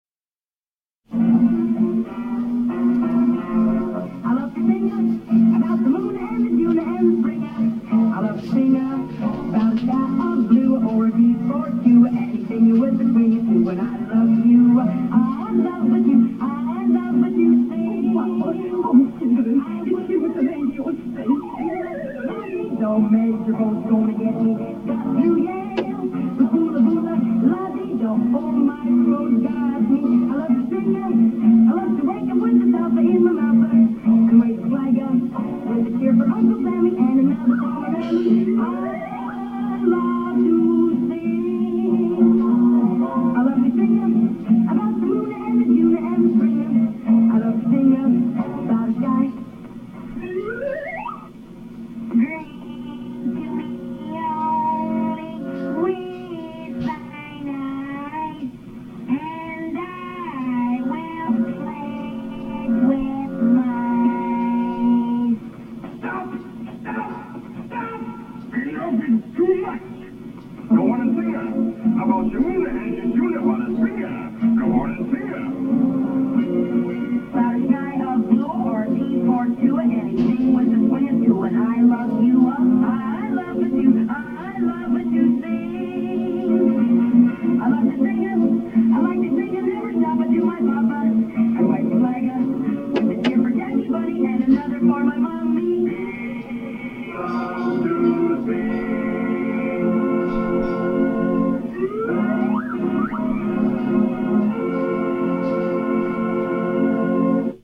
This CD was recorded at TallMan Studios in 1988.
snapped her fingers
and sang the lead vocals on all of the songs.